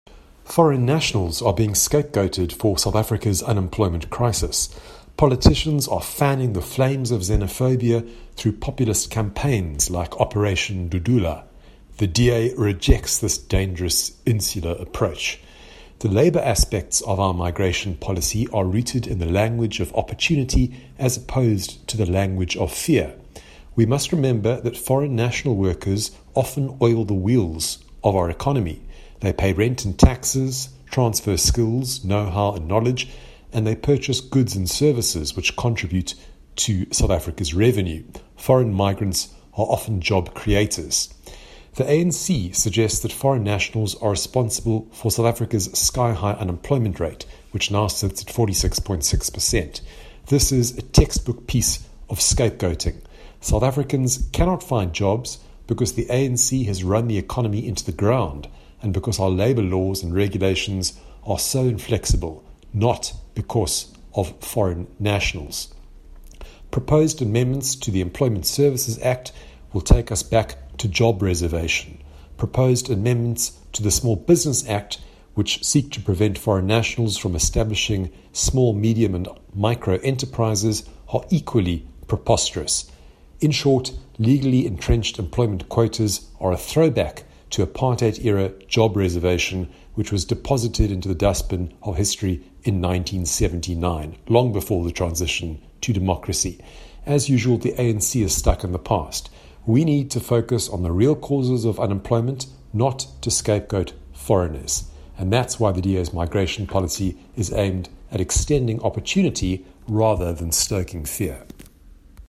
Please find attached soundbites by Gwen Ngwenya DA Head of Policy;